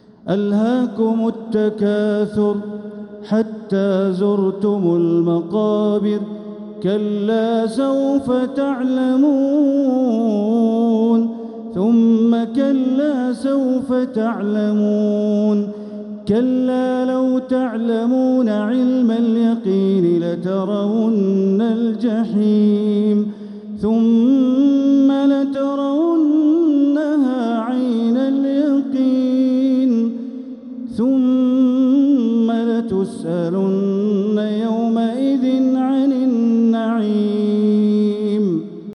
سورة التكاثر | مصحف تراويح الحرم المكي عام 1446هـ > مصحف تراويح الحرم المكي عام 1446هـ > المصحف - تلاوات الحرمين